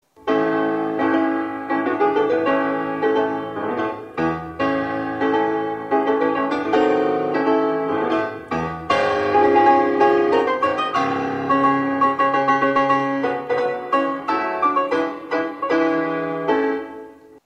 Первым звучит полонез ля-мажор.